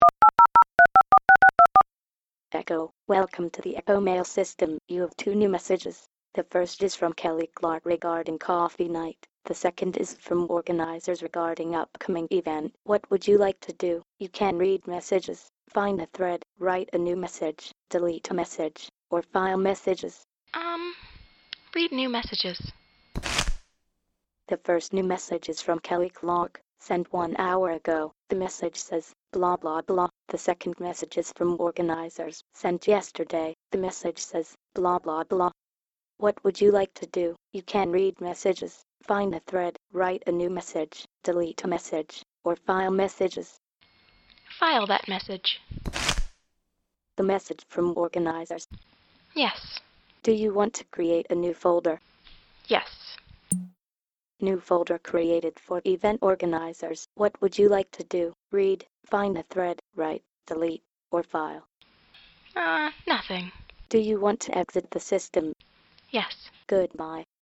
A voice based e-mail system that can be accessed over the phone or as an environmental agent. Ekhomail incorporates context-based commands through a conversational user interface developed on top of human language characteristics.